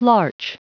Prononciation du mot larch en anglais (fichier audio)
Prononciation du mot : larch